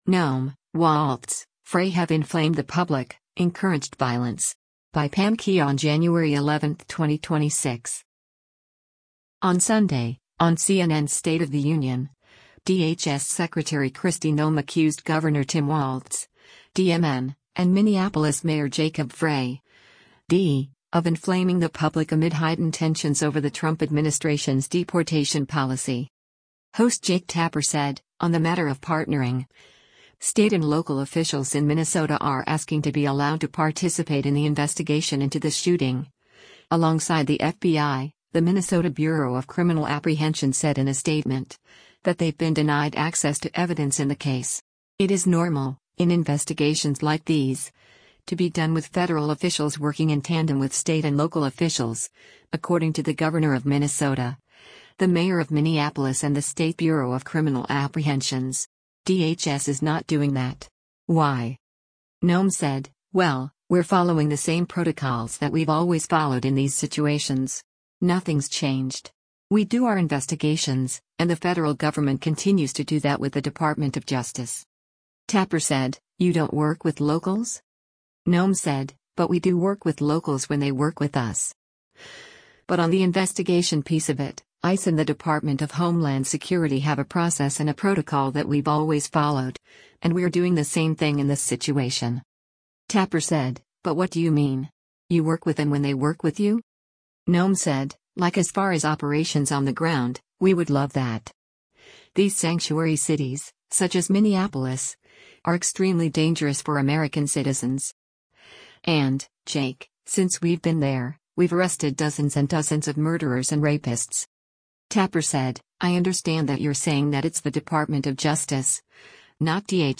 On Sunday, on CNN’s “State of the Union,” DHS Secretary Kristi Noem accused Gov. Tim Walz (D-MN) and Minneapolis Mayor Jacob Frey (D) of inflaming the public amid heightened tensions over the Trump administration’s deportation policy.